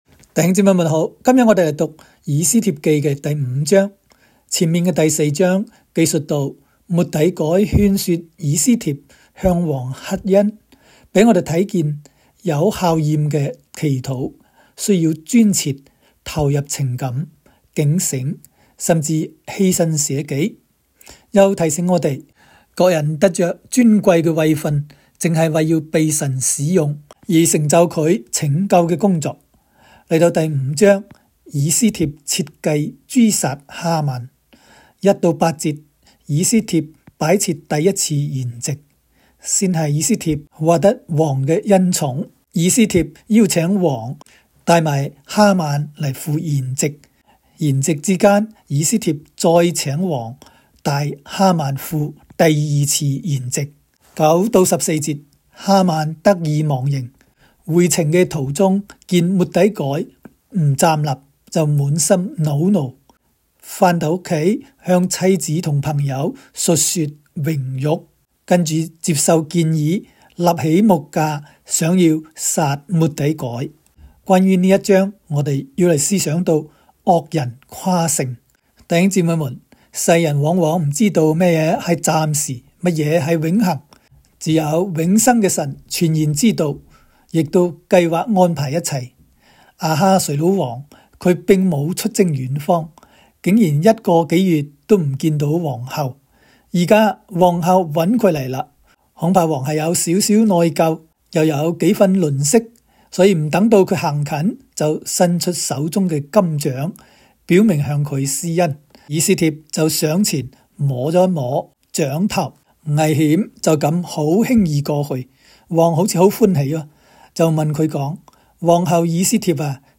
斯05（讲解-粤）.m4a